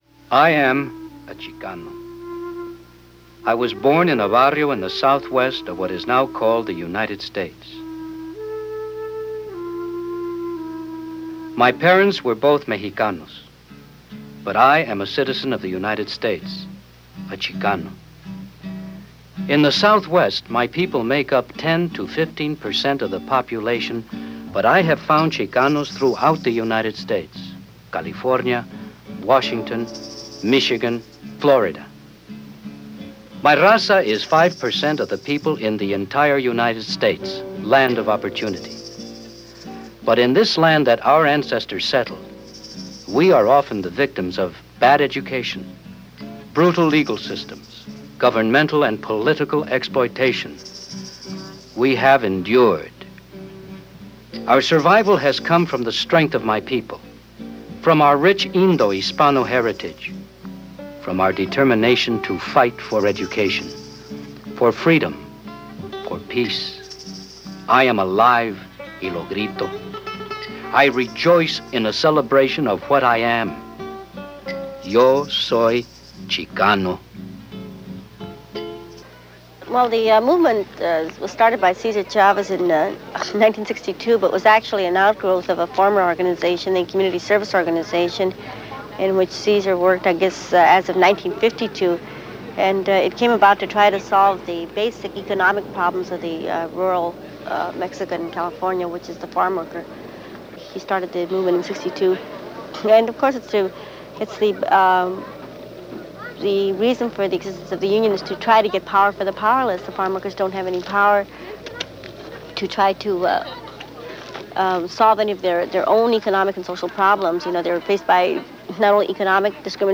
Yo Soy Chicano - Documentary on Mexican-Americans and Migrant workers in America in the 1970s.